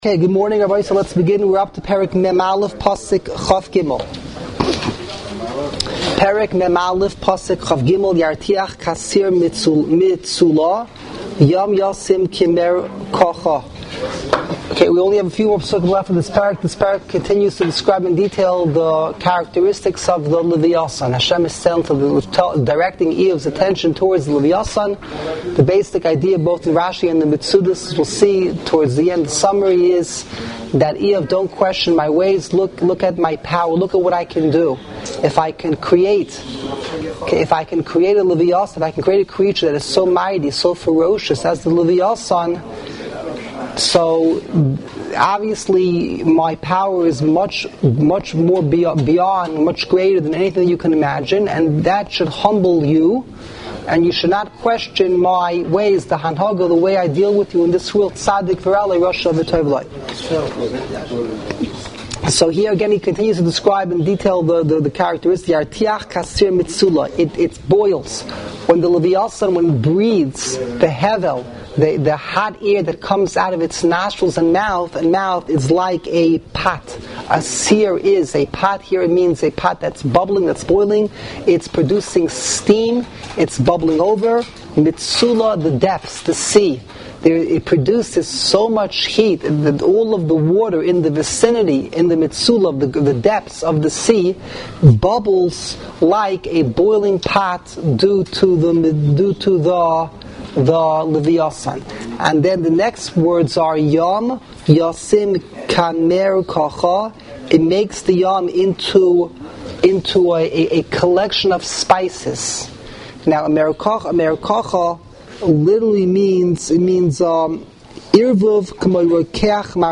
Live Daily Shiurim